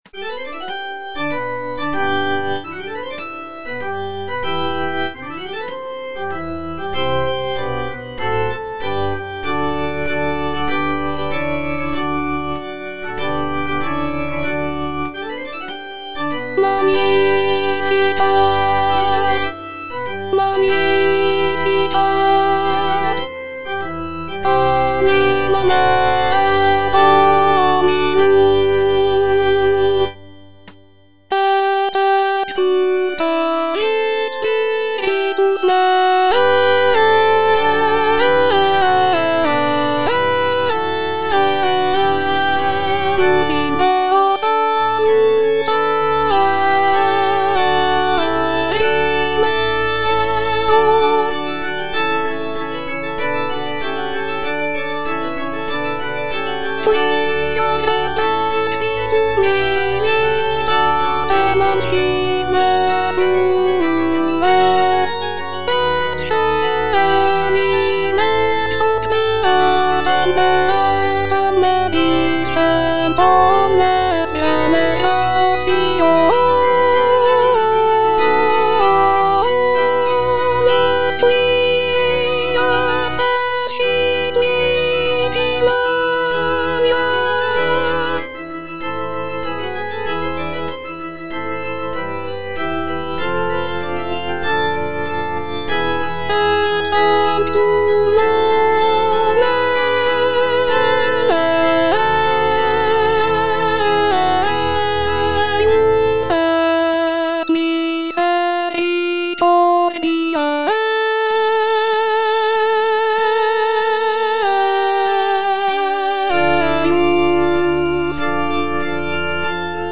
B. GALUPPI - MAGNIFICAT Magnificat anima mea - Alto (Voice with metronome) Ads stop: auto-stop
The work features intricate vocal lines, rich harmonies, and dynamic contrasts, creating a powerful and moving musical experience for both performers and listeners.